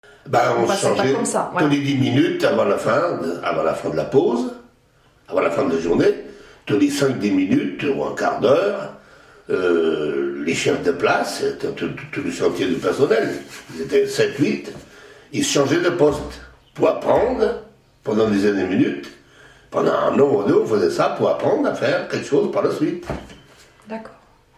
Figure 03 : Extrait vidéo du témoignage d’un ouvrier verrier.